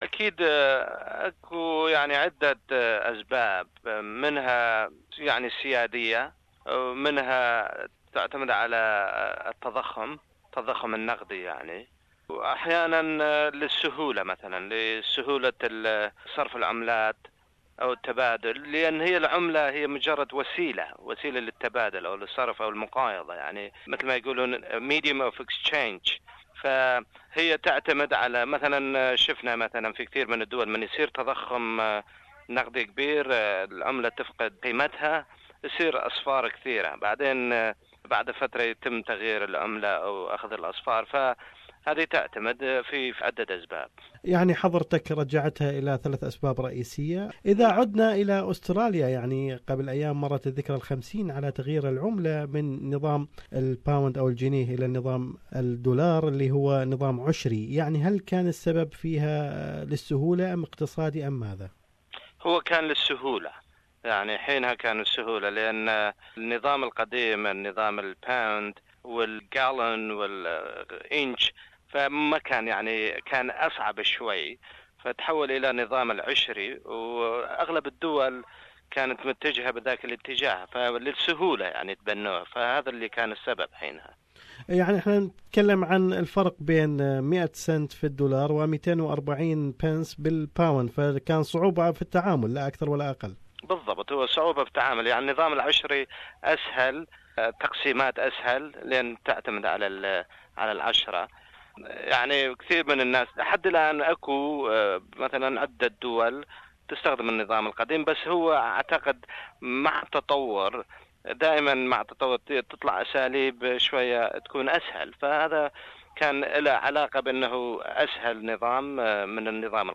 بالباحث في الاقتصاد السياسي